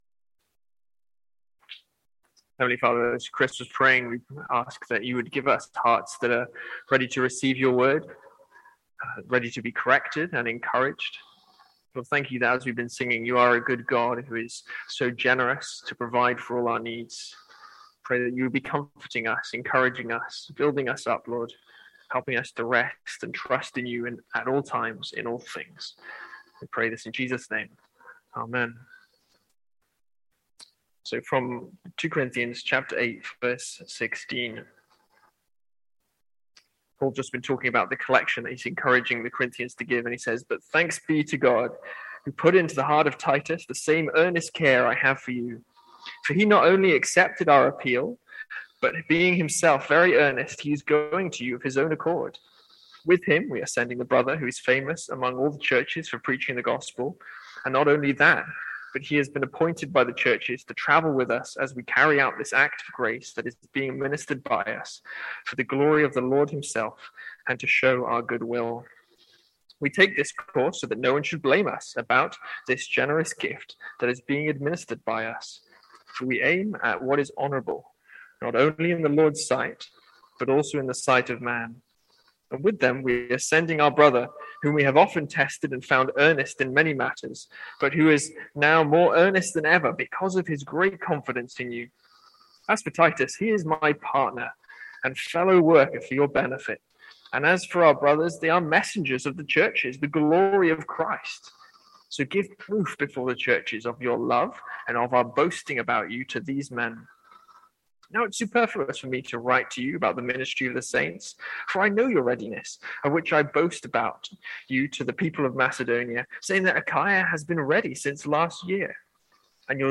Sermons | St Andrews Free Church
From our evening series in 2 Corinthians.